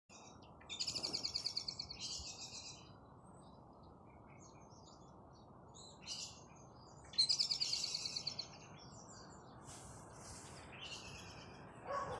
черный дрозд, Turdus merula
Ziņotāja saglabāts vietas nosaukumsRīga, Zasulauks
Skaits2
СтатусВзволнованное поведение или крики